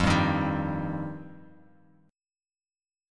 F7sus2 chord